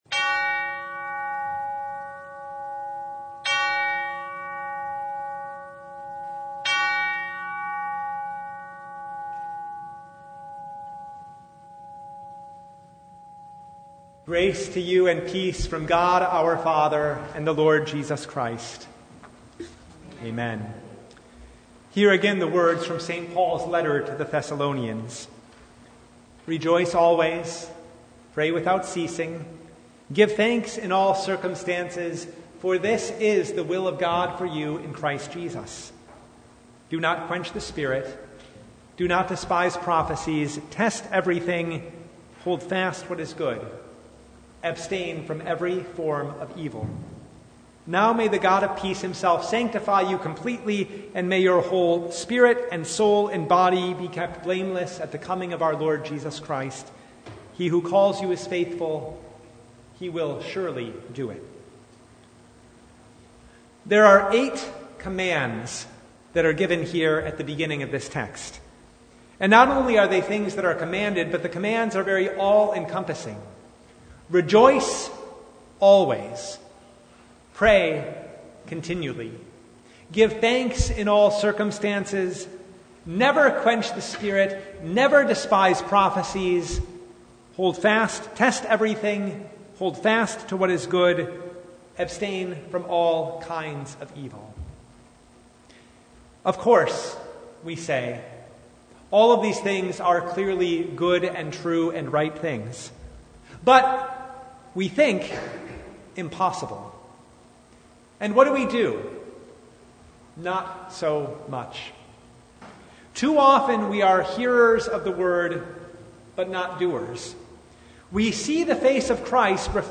1 Thessalonians 5:16-24 Service Type: Advent Vespers Rejoice always?